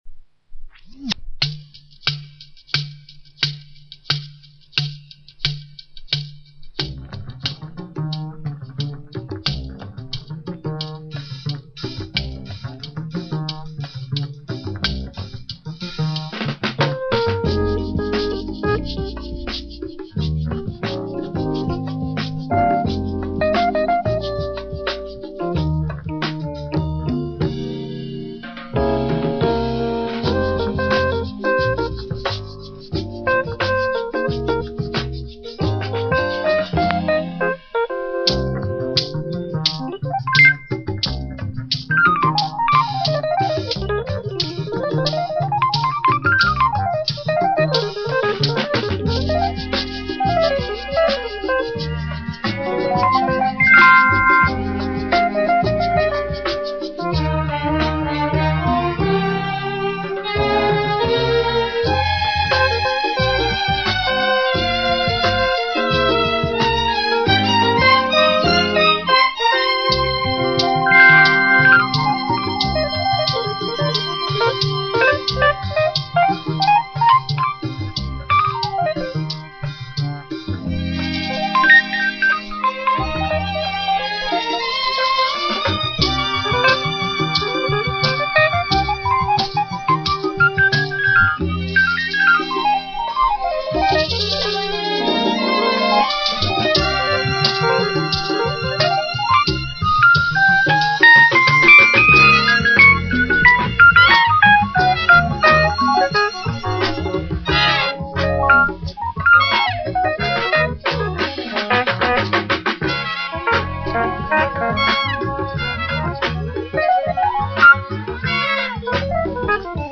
mix